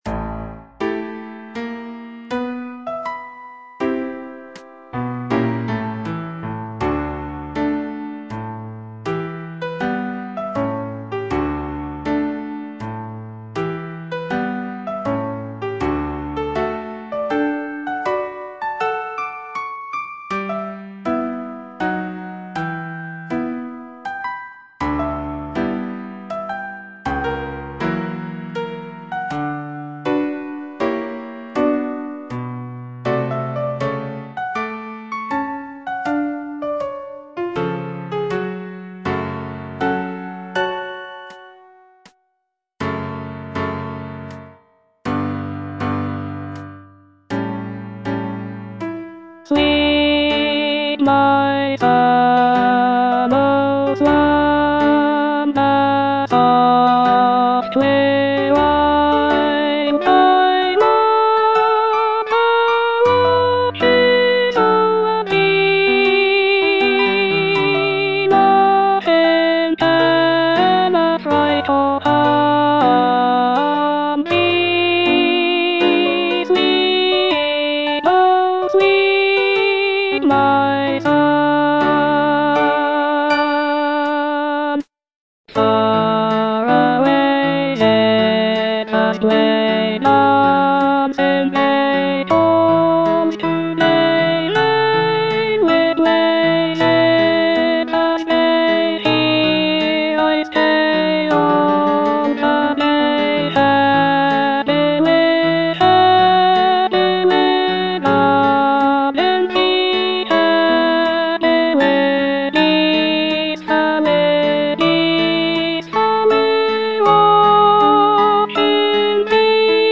E. ELGAR - FROM THE BAVARIAN HIGHLANDS Lullaby (alto I) (Voice with metronome) Ads stop: auto-stop
The piece consists of six choral songs, each inspired by Elgar's travels in the Bavarian region of Germany.